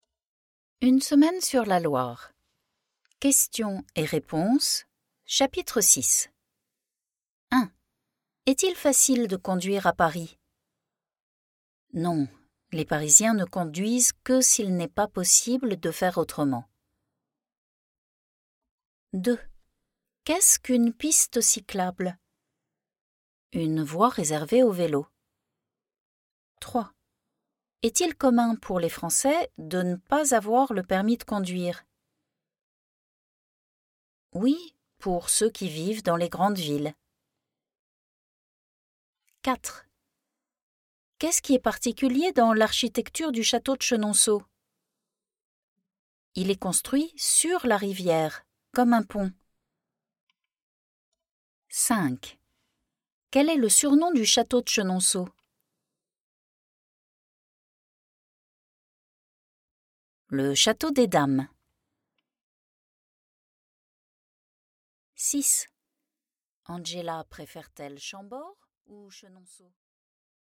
This French audiobook also includes a recorded Q&A section to practice your French out loud and check your understanding of the story.
8 Hours 22 Mins of story chapters + Q&A exercises – all professionally recorded by a Parisian native + 186-page transcript with English translation.
Chapter 6 Questions & Answers